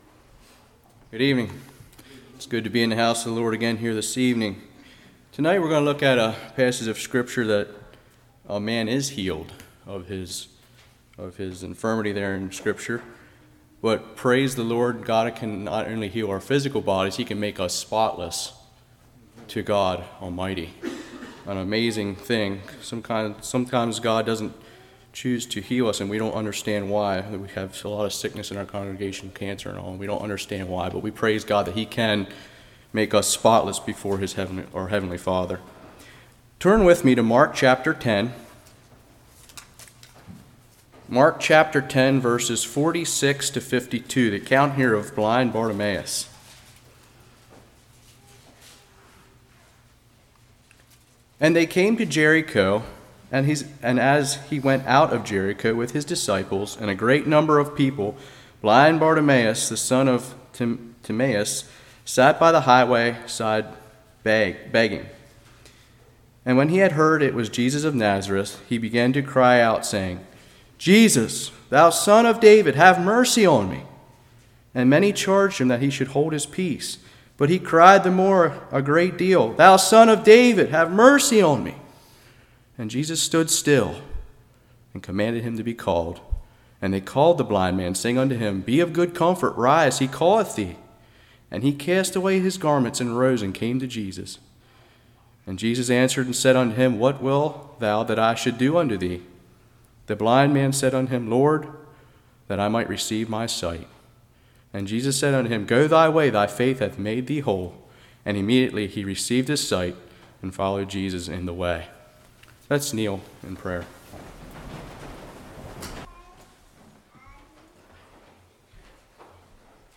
January 26, 2020 Crying Out to God Passage: Mark 10:46-52 Service Type: Evening Who should cry out to God and how?